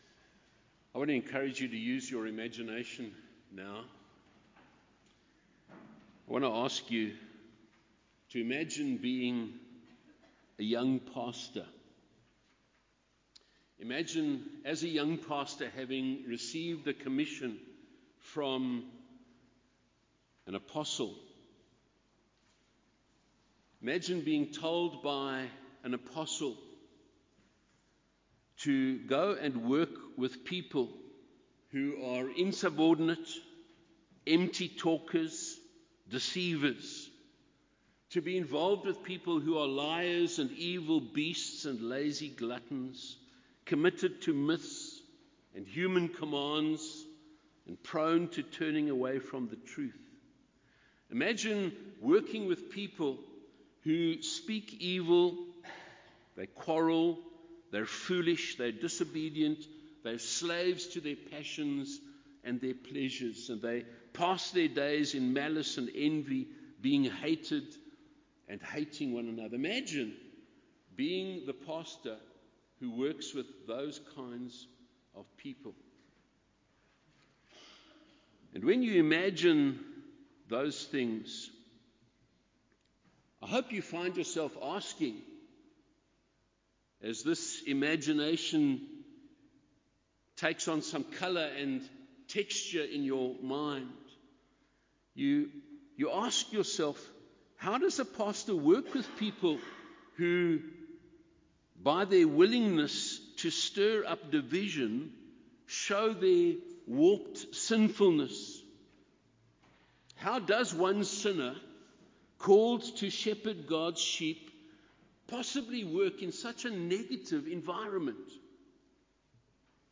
Sermons under misc. are not part of a specific expositional or topical series.